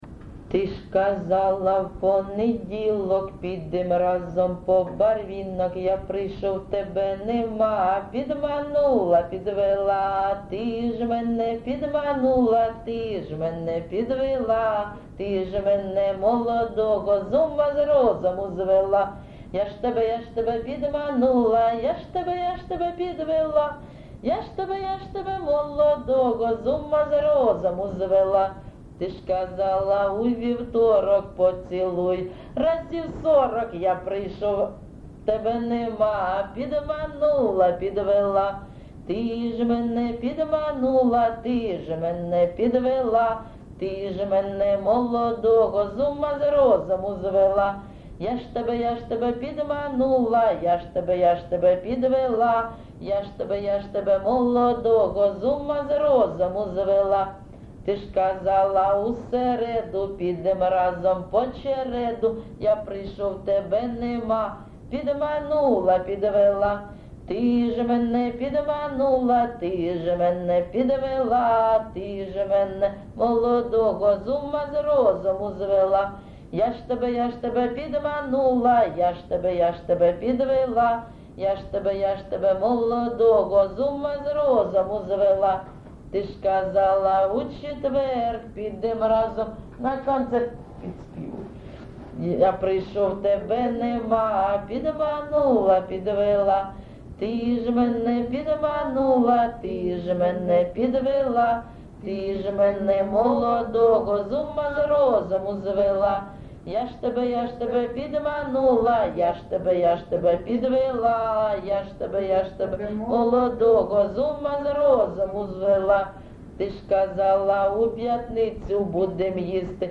ЖанрЖартівливі
Місце записус-ще Олексієво-Дружківка, Краматорський район, Донецька обл., Україна, Слобожанщина